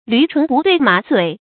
注音：ㄌㄩˊ ㄔㄨㄣˊ ㄅㄨˊ ㄉㄨㄟˋ ㄇㄚˇ ㄗㄨㄟˇ
驢唇不對馬嘴的讀法